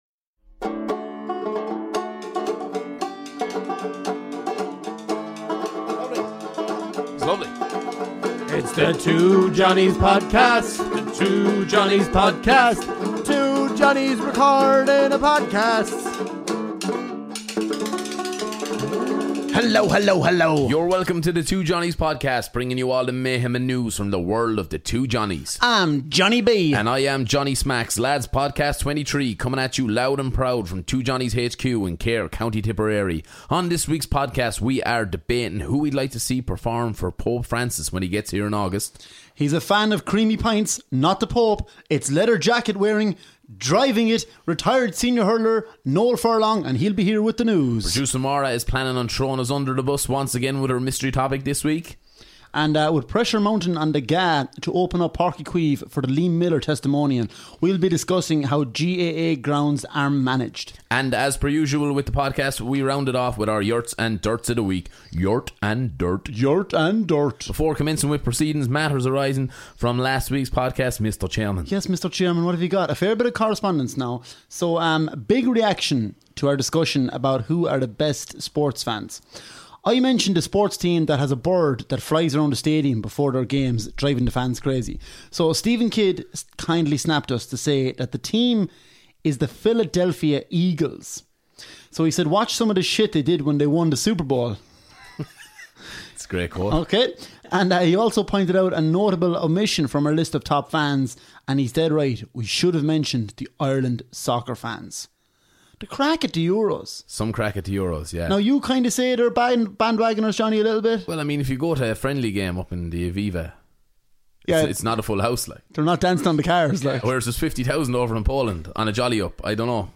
Ireland's favourite comedy duo tackle the big issues, this week:The Pope is coming to Ireland, all you need to know for surviving the big mass, who we'd like to see preform at it and our thoughts on it.